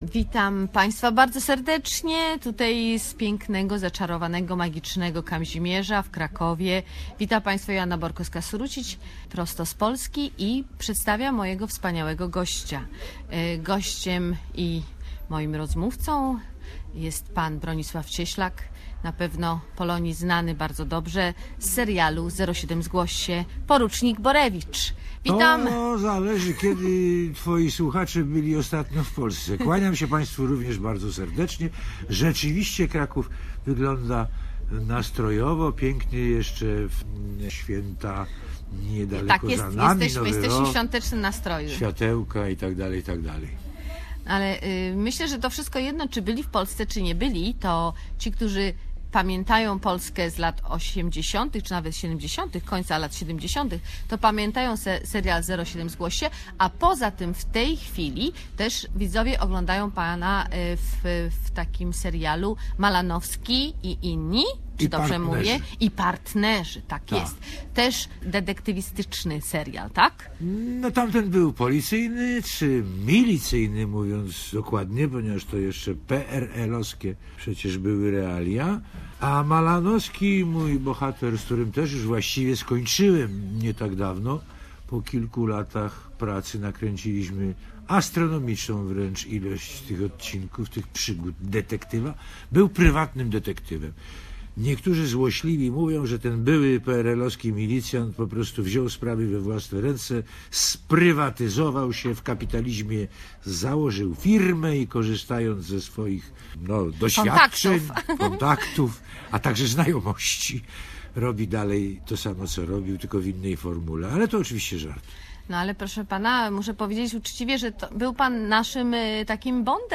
The well-known Polish actor Bronislaw Cieslak tells story about his work at highly popular Polish criminal TV's series "07 zgłoś się".